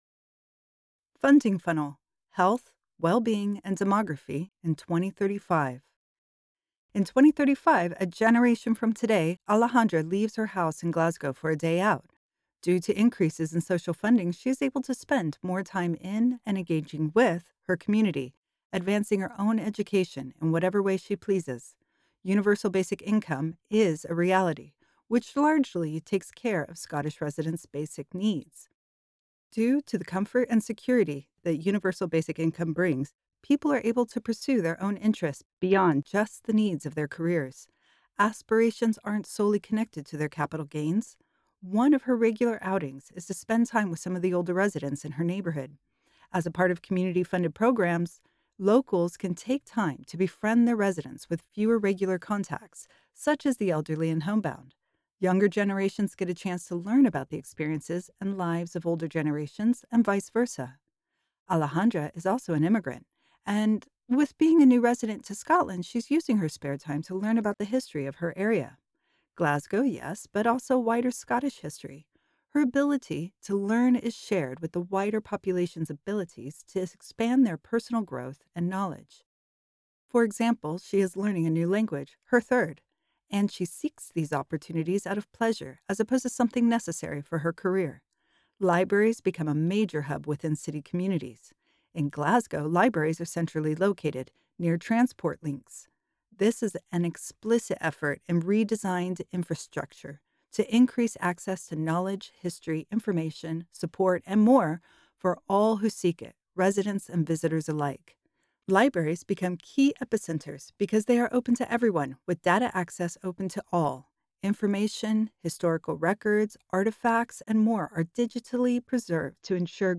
Audio narration of scenario “Energy Kilometers”